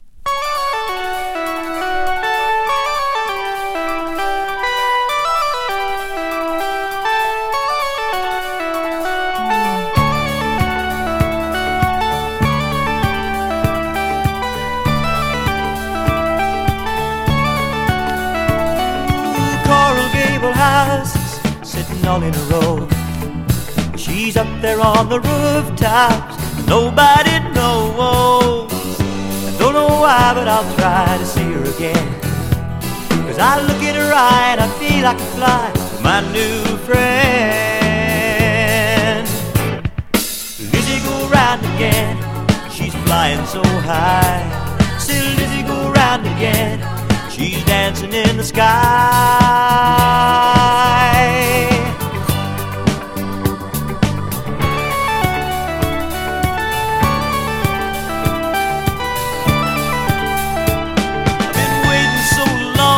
カントリー二人組のブルーアイドソウル・アルバム。